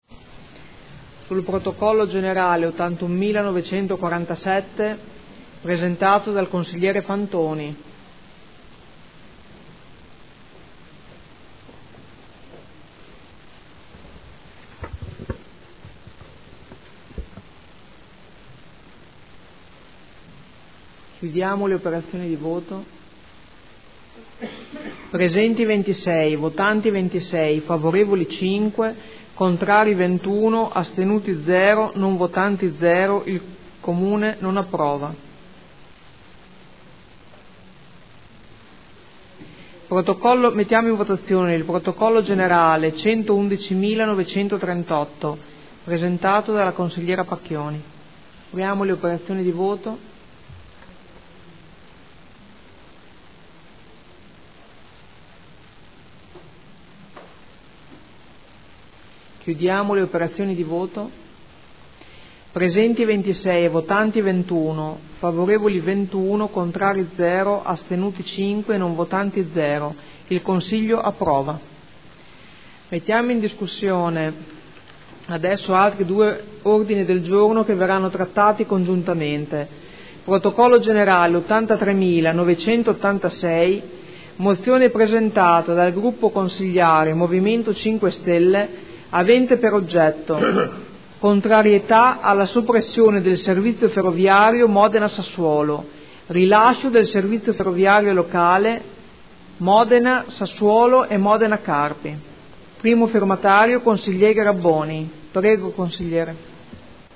Seduta del 16 ottobre. Votazione ordini del giorno N°81947 e N°111938